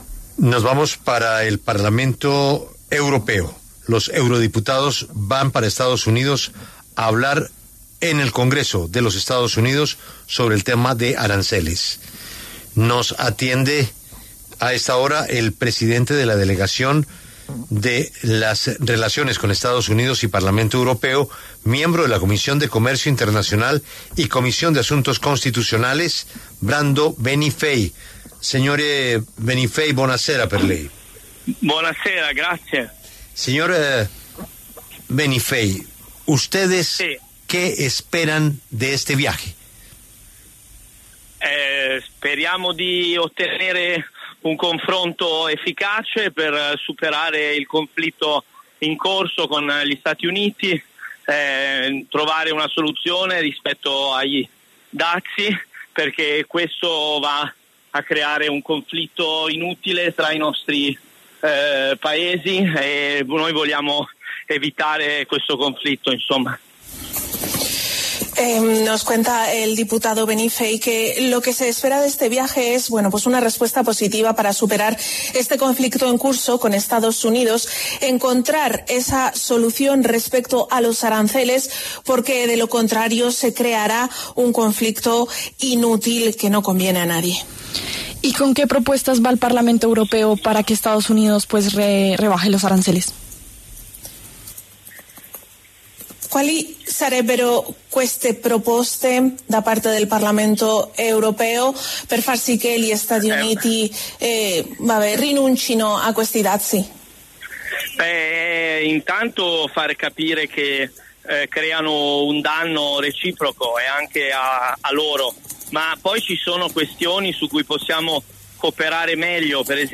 Por este motivo, en los micrófonos de La W, con Julio Sánchez Cristo, habló el presidente de la Delegación para las Relaciones con EE.UU. del Parlamento Europeo, Brando Benifei, quien se refirió al tema.